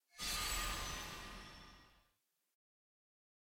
sfx-eog-lobby-player-returned.ogg